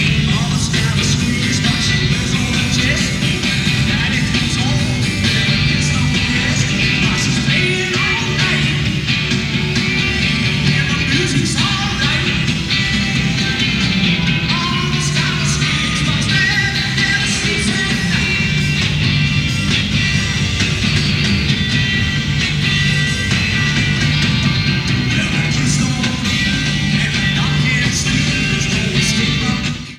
Format/Rating/Source: CD - C- - Audience
Comments: Fair audience recording
Sound Samples (Compression Added):